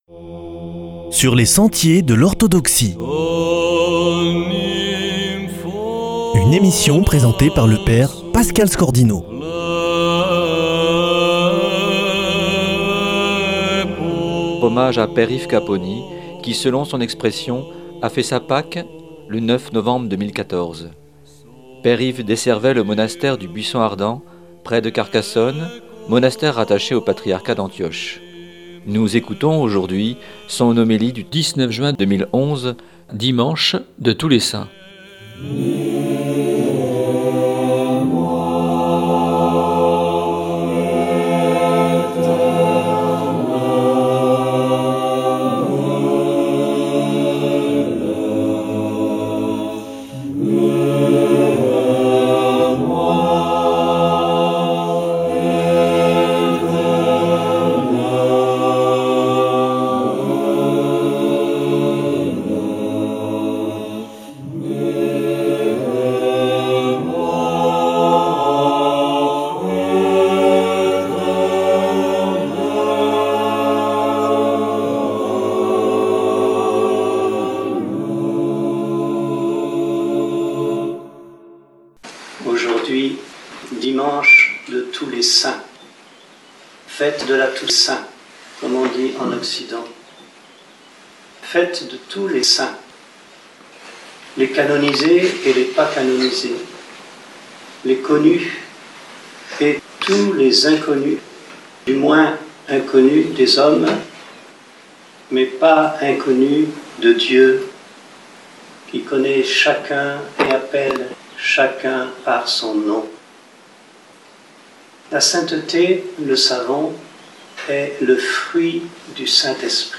Dimanche de tous les Saints